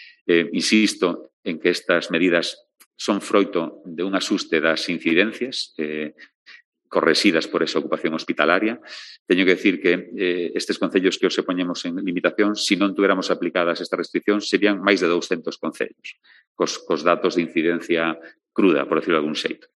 El conselleiro de Sanidade explica que las "tasas ajustadas" permiten evitar medidas más drásticas